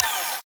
Sfx_tool_spypenguin_exit_01.ogg